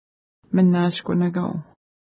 Image Not Available ID: 338 Longitude: -59.1302 Latitude: 53.7191 Pronunciation: məna:ʃkunəka:w Translation: Wooded Island Official Name: Cabot Island Feature: island Explanation: The island is full of fir-trees.